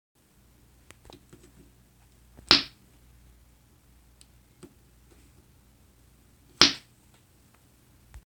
Field Recording #3
Suction Cup